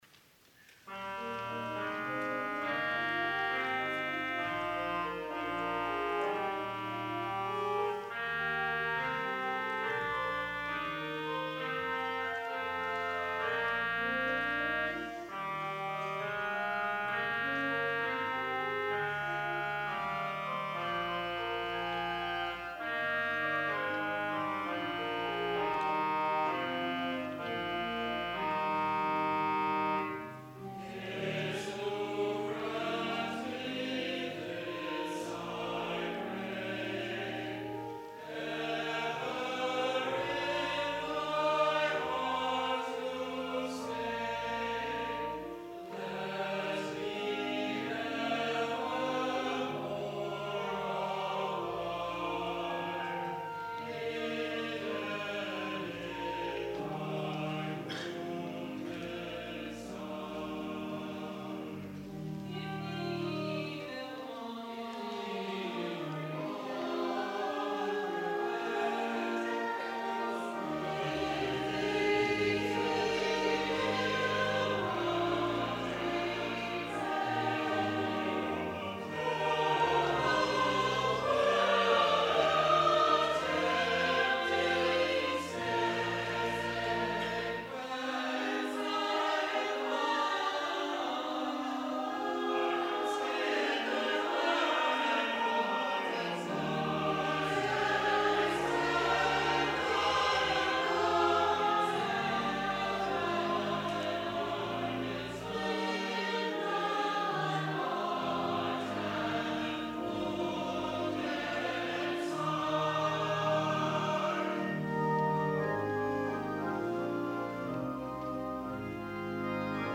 ANTHEM Jesu, Grant Me This, I Pray Charles Herbert Kitson, 1874-1944
Chancel Choir
organ